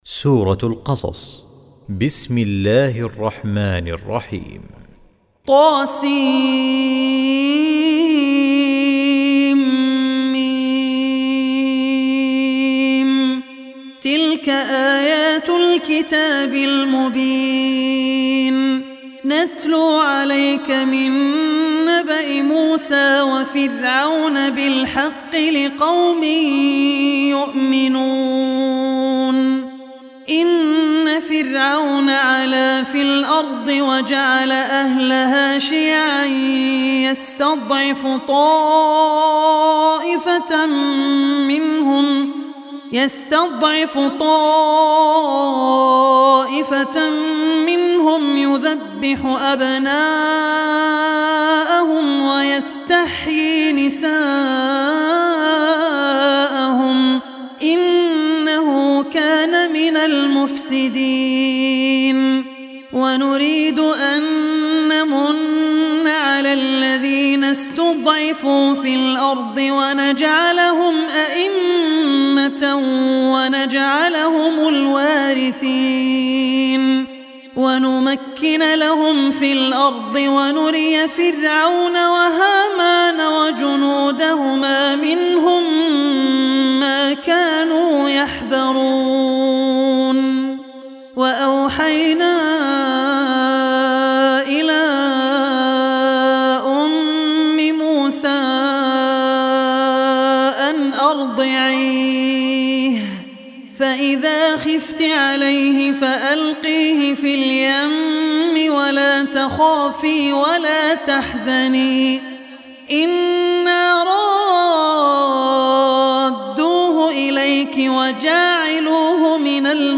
Surah Al-Qasas Recitation by Abdul Rehman Al Ossi
Surah Al-Qasas, listen online mp3 tilawat / recitation in the voice of Sheikh Abdul Rehman Al Ossi.